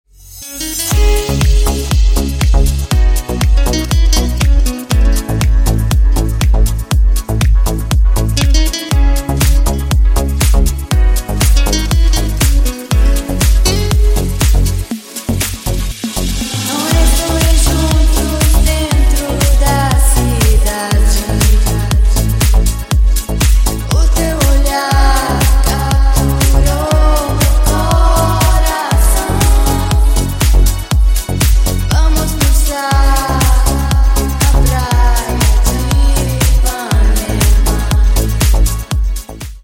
Клубные Рингтоны » # Латинские Рингтоны
Танцевальные Рингтоны